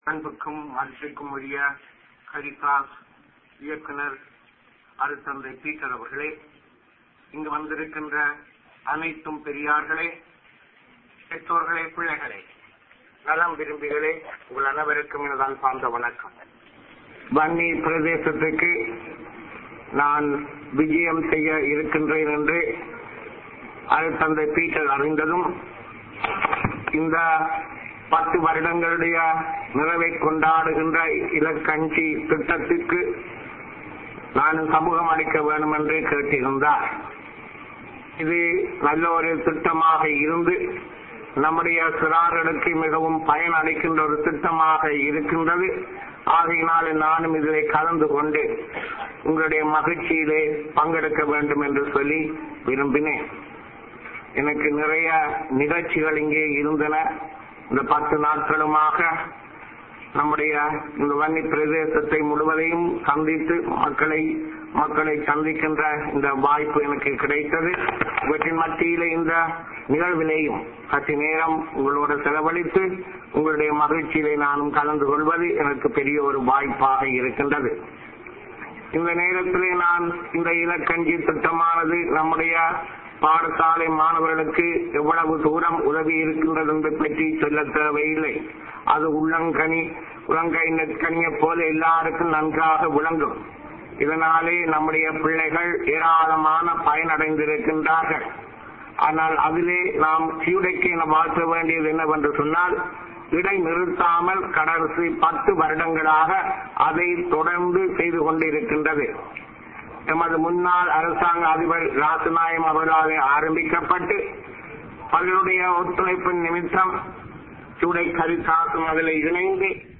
"It is extremely difficult for the people to enjoy their rights and dignity as equal citizens of a country as long as Sinhala Buddhist chauvinism prevails here," said the Bishop of Jaffna, Rev. Dr. Thomas Saundaranayagam Tuesday, addressing a gathering to mark the tenth year completion of the service in providing nutrition feeding to schoolchildren in Vanni by the Human Development Centre (HUDEC-Caritas), the social arm of the Catholic Church of Jaffna, sources in Vanni said.
Voice: Rev. Dr. Thomas Saundaranayagam's address